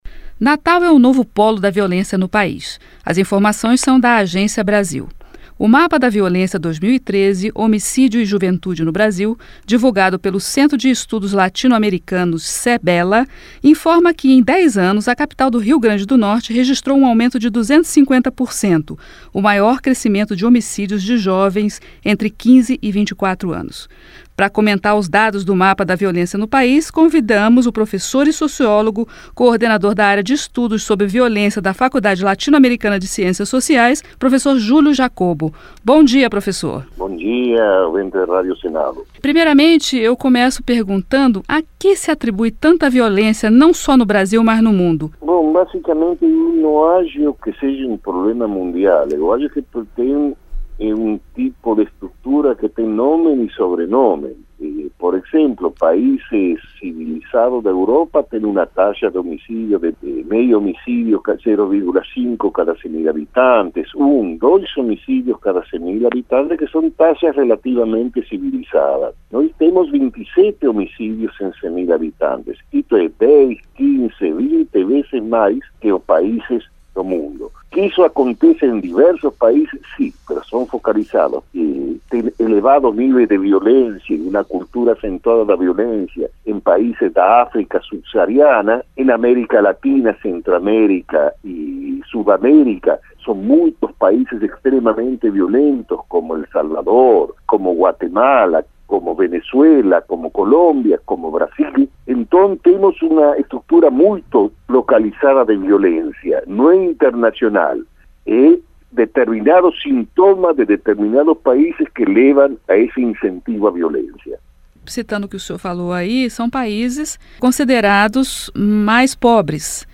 Entrevista: Altos índices de violência decorrem grande desigualdade social